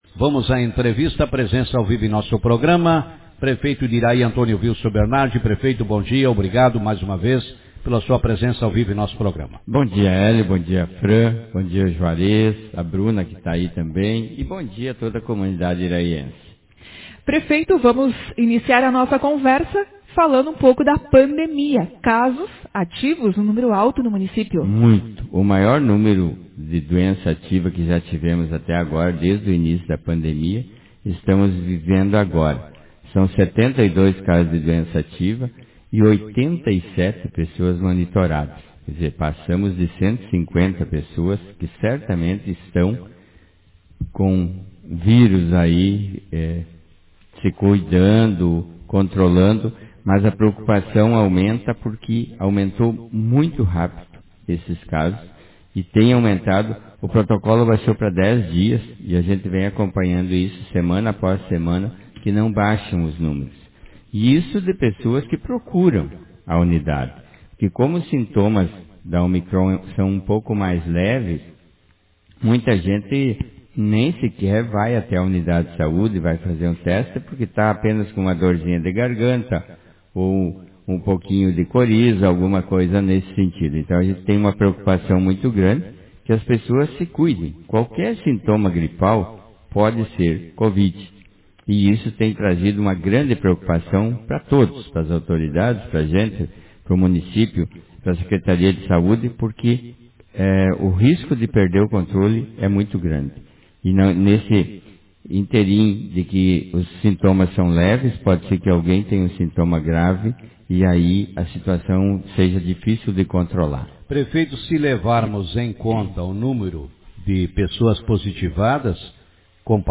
Prefeito de Iraí afirma que cuidados devem ser intensificados com relação ao coronavírus Autor: Rádio Marabá 15/02/2022 Manchete Em entrevista concedida na manhã desta terça-feira, 15, no programa Café com Notícias, o prefeito de Iraí, afirmou que a Secretaria da Saúde deve manter e ainda intensificar os cuidados com o coronavírus.